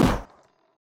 gun.wav